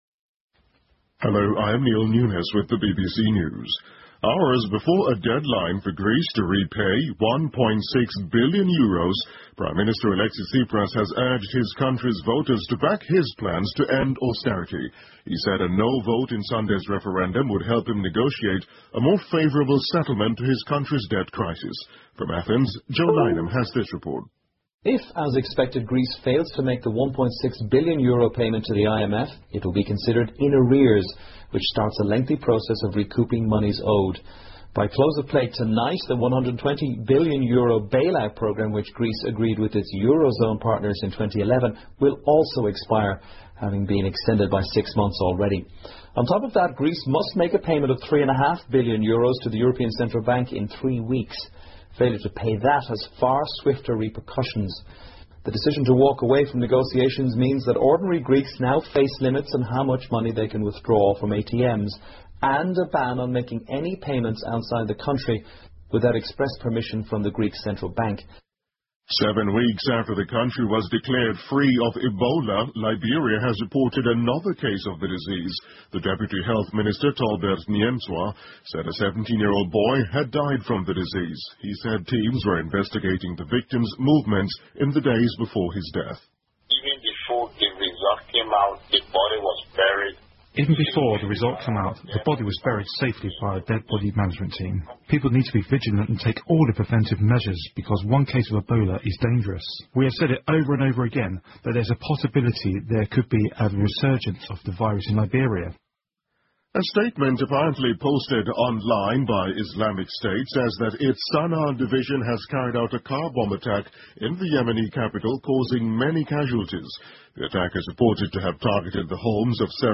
英国新闻听力 利比里亚报道出现埃博拉新病例 听力文件下载—在线英语听力室